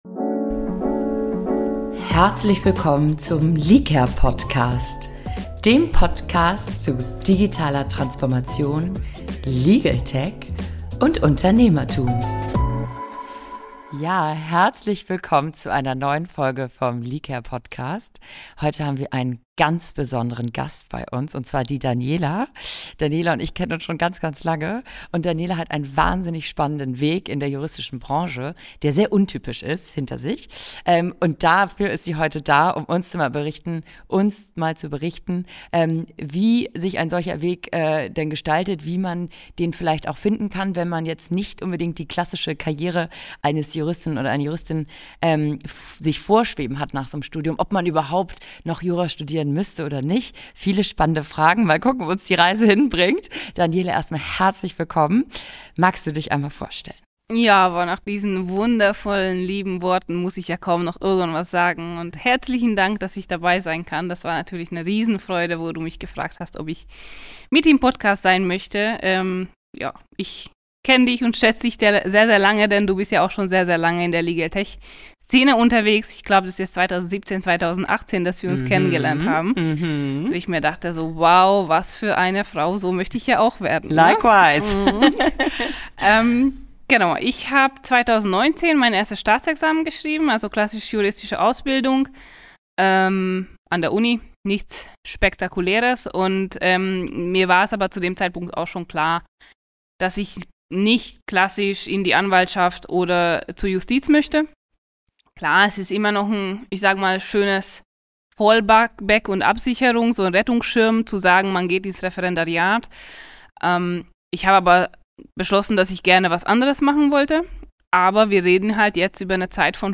auf dem German Legal Tech Summit in Hannover gewidmet.